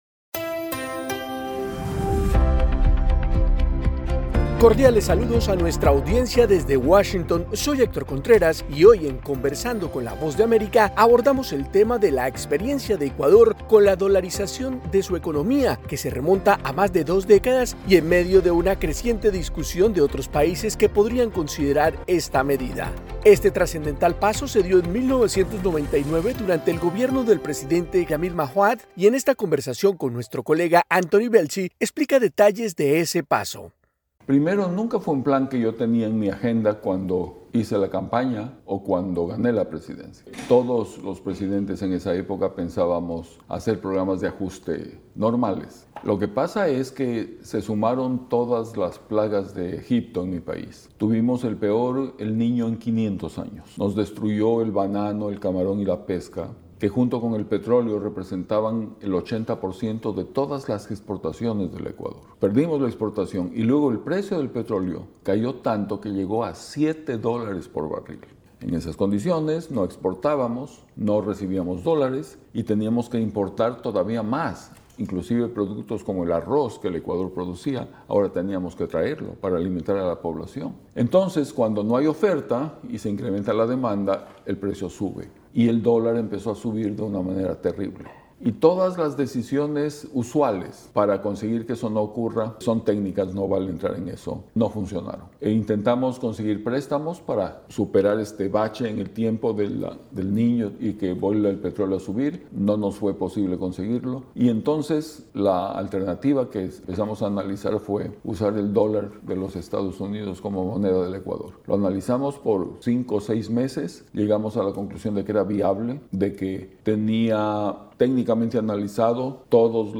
Conversamos con Jamil Mahuad, expresidente de Ecuador, destacando detalles de la medida de dolarización que tomó en su presidencia en 1999 y que ahora está en un libro que presentó en la Feria del Libro de Miami.